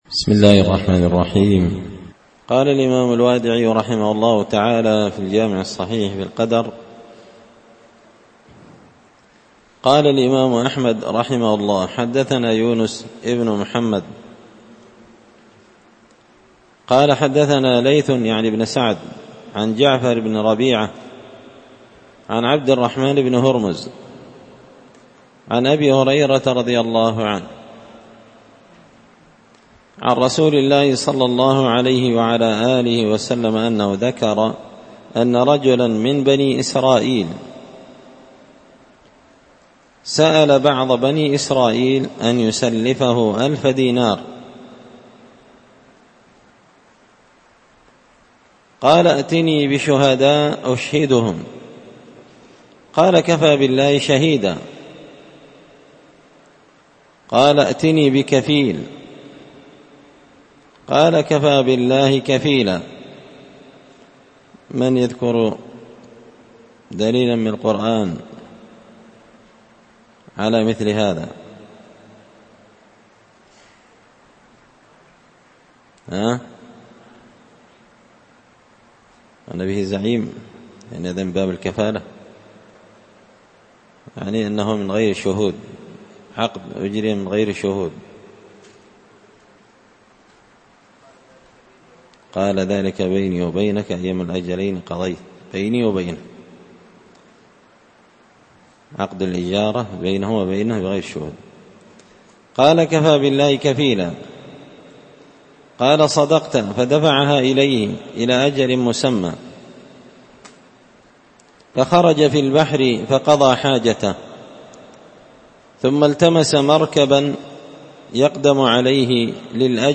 الدرس 116 فصل من عجائب قدرة الله الخارقة للعادة
دار الحديث بمسجد الفرقان ـ قشن ـ المهرة ـ اليمن